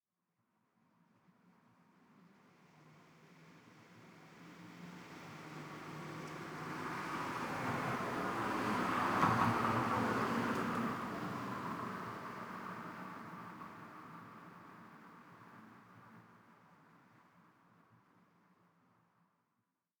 1Shot Vehicle Passby with Tire Bumps ST450 03_ambiX.wav